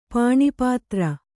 ♪ pāṇipātra